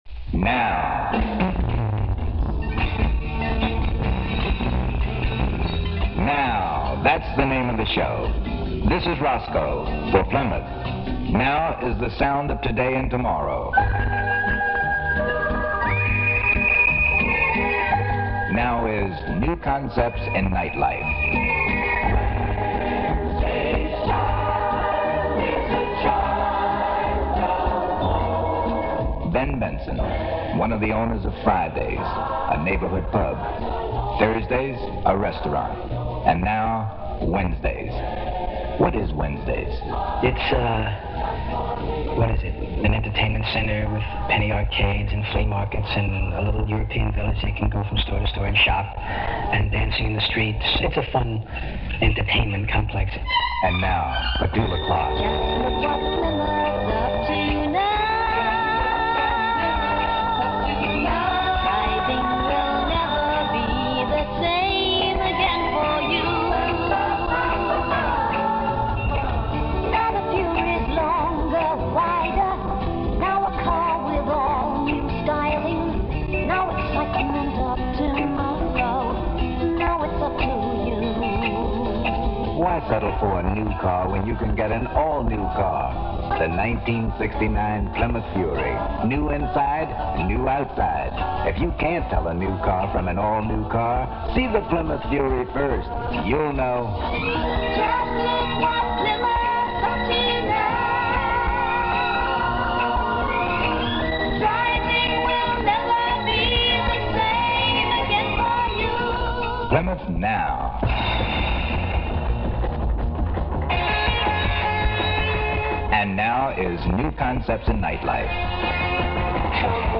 Caveat: The sound is rather terrible – the disc it came from was partially destroyed.
The interviews are priceless, as is the Plymouth commercial with Petula Clark.